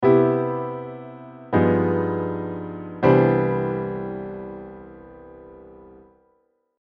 同じドミナントモーションでもテンションさんを使うと
こんなに変わる んですよっ。
さっきと印象が全然かわるよっ。